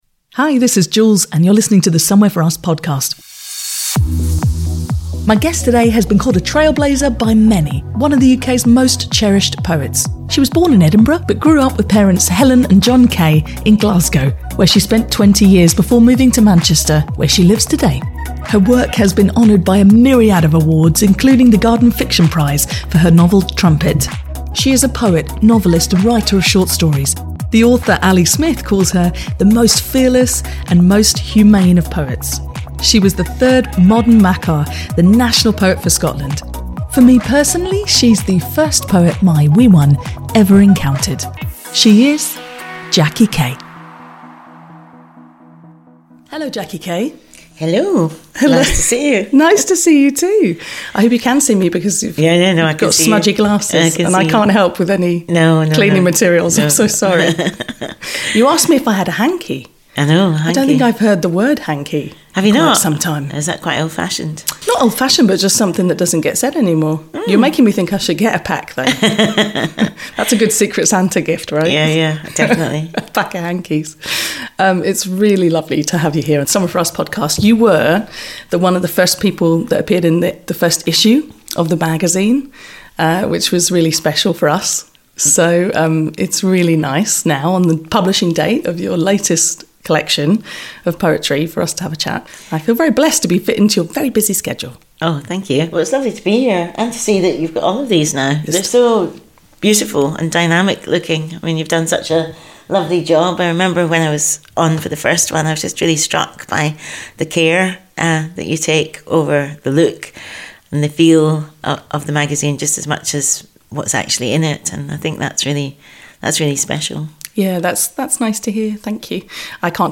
We record at my home (ten points if you can hear the bin lorry during the chat!) and Jackie was kind enough to come over and share the stories behind this new collection of poems, and its title, ‘May Day’, a chronicle of activism in the UK over six decades. It’s a feisty conversation, with peaks of revelry as Jackie recalls her encounter with Maya Angelou (doing an impeccable impression of her) and deeper, quieter and more sombre moments for both Jackie and I, as we reflect on loss, feelings about family and the notion of home.